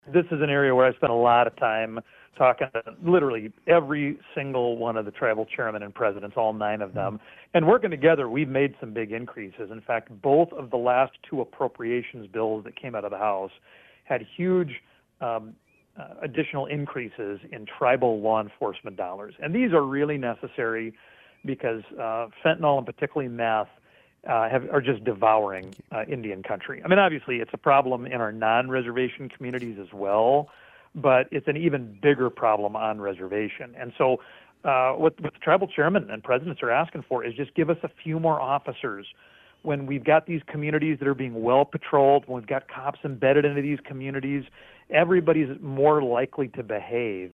Another state is that within tribal lands, the recidivism rate is sitting at 66%.  Congressman Johnson reacted to that.